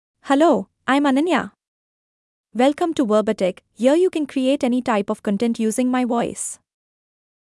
FemaleEnglish (India)
Ananya is a female AI voice for English (India).
Voice sample
Ananya delivers clear pronunciation with authentic India English intonation, making your content sound professionally produced.